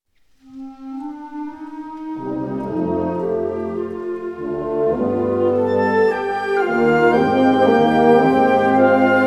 Bezetting Ha (harmonieorkest)